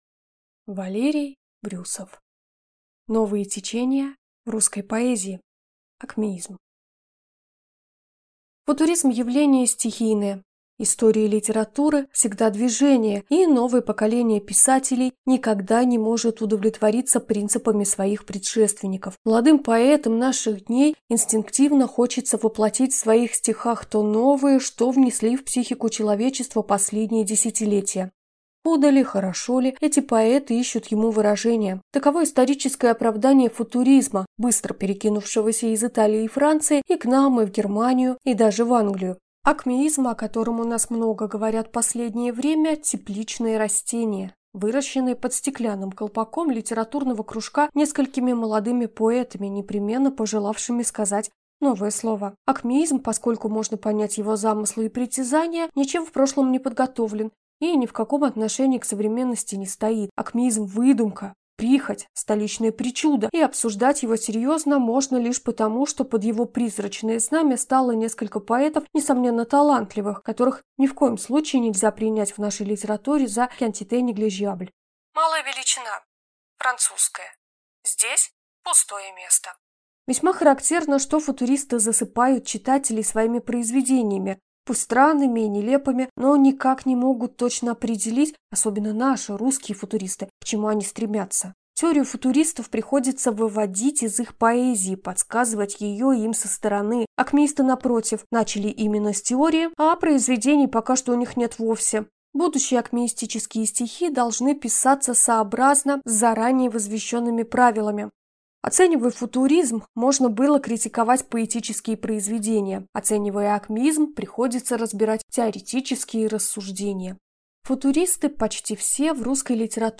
Аудиокнига Новые течения в русской поэзии. Акмеизм | Библиотека аудиокниг